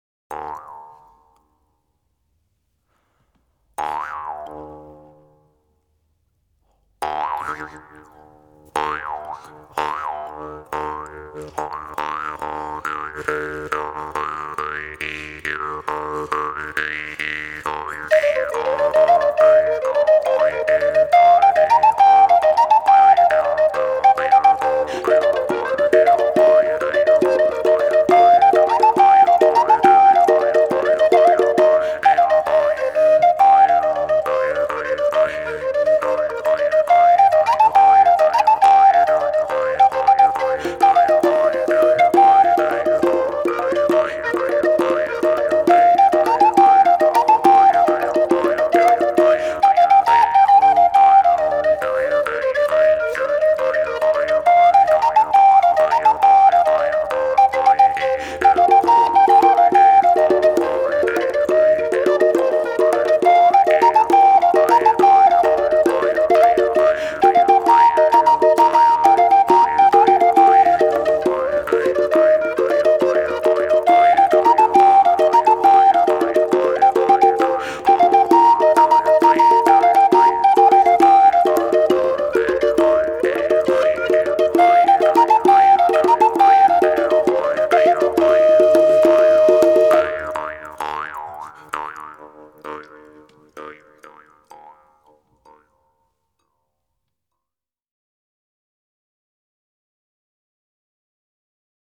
Saltarellos
Danza
Música tradicional